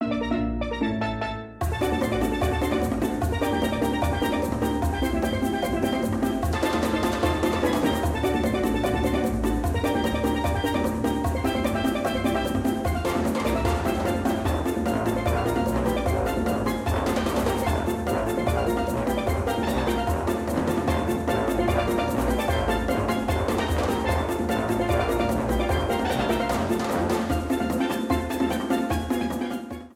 Ripped from the game
Trimmed to 30 seconds with fadeout